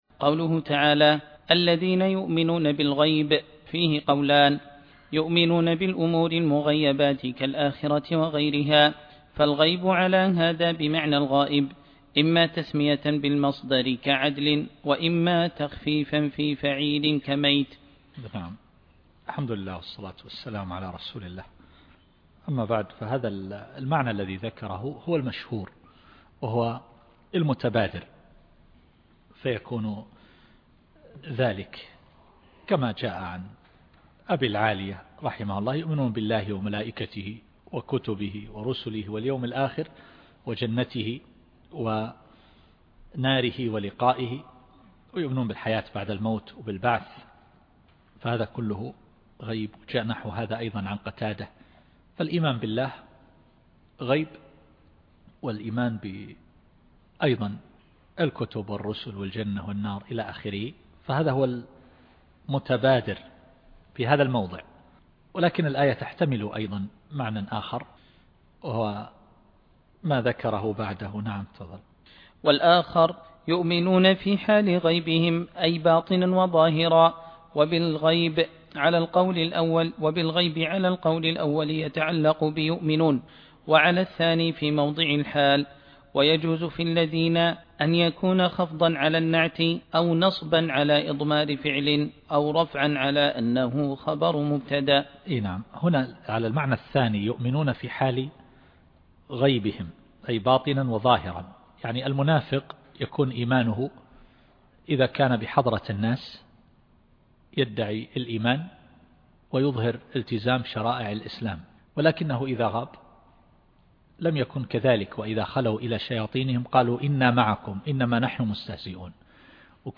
التفسير الصوتي [البقرة / 3]